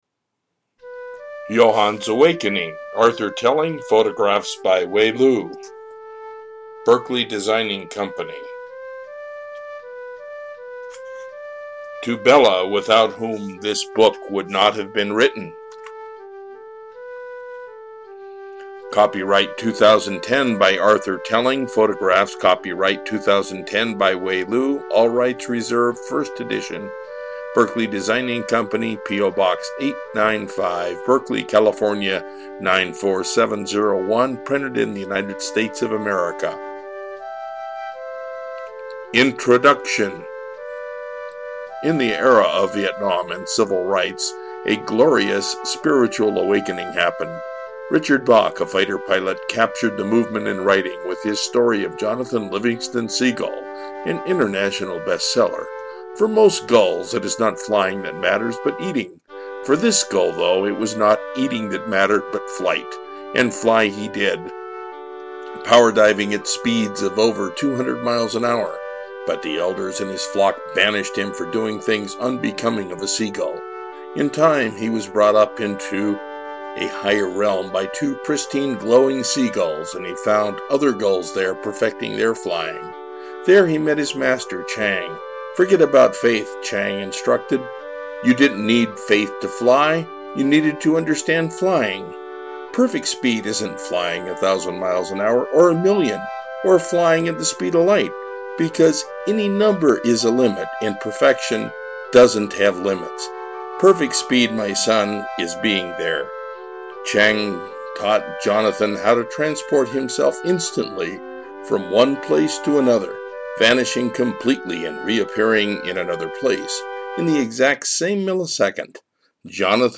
Johann's Awakening - Audiobook